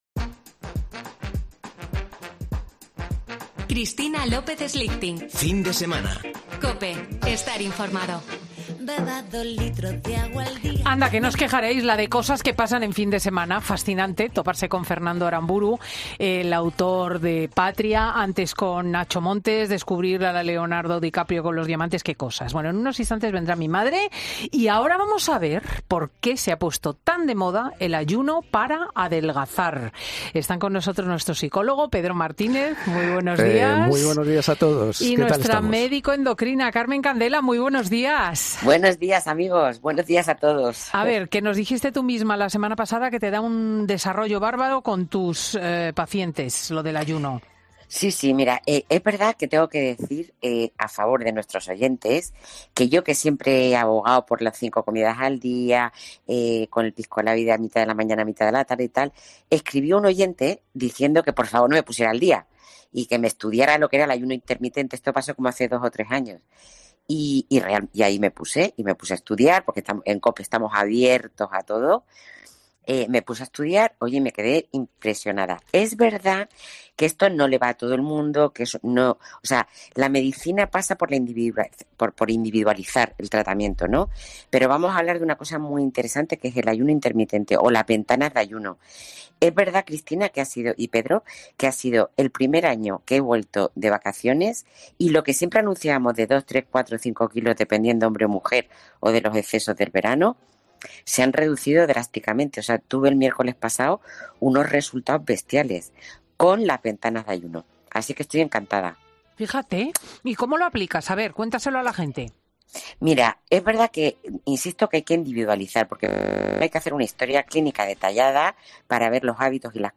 Puedes volver a escuchar a los dos especialistas dar todas las claves sobre el ayuno intermitente en el audio que tienes aquí disponible.